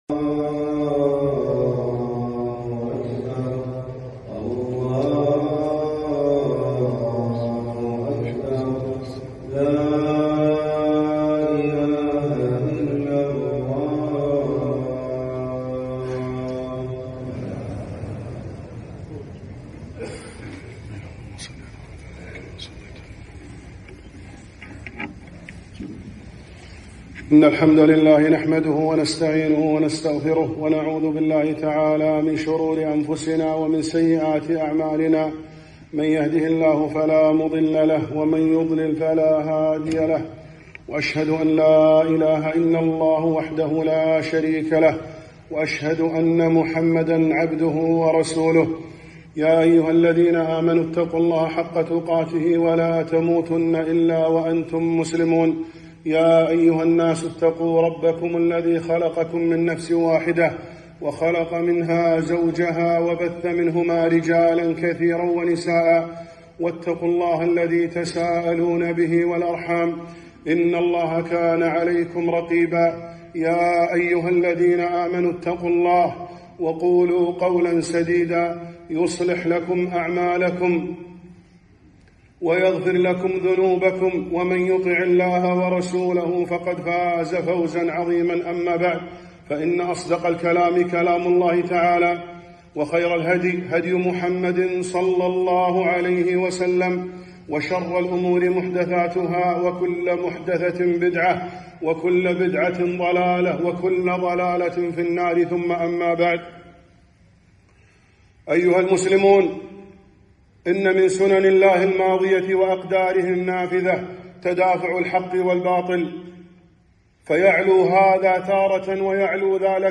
خطبة - فضل الرباط والمرابطين